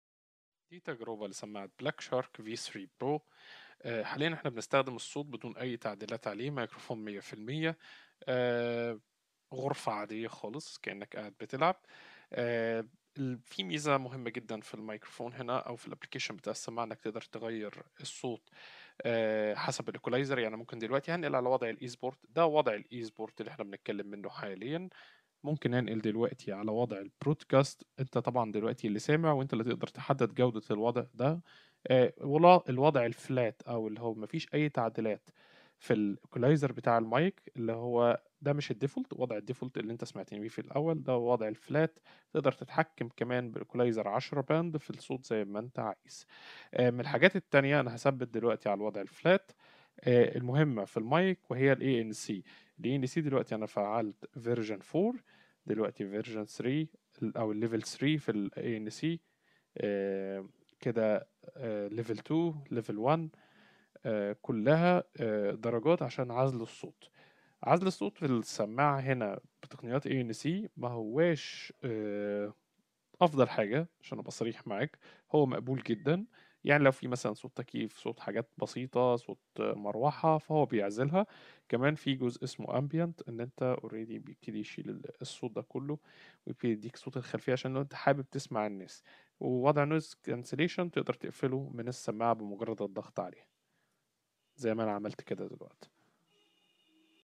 الميكروفون الخاص بالسماعة يعتبر من أفضل السماعات التي تملك ميكروفون بهذا الحجم وهذا الوضوح مع إمكانيات ممتازة للتحكم في الصوت من البرنامج الملحق ،  فالصوت الصادر نقى وواضح للغاية اما العزل الخاص بالسماعة بين جيد و جيد جداً ولكن بالتأكيد يوجد أفضل من هذا بسبب تصميم السماعة و الزراع المعدنية علي شكل حرف Y .
صوت الميكروفون : صوت جيد جدا مع عزل الضوضاء المحيطة بصورة جيدة جدا  أيضاً ويمكن تقييمة بـ 8.5/10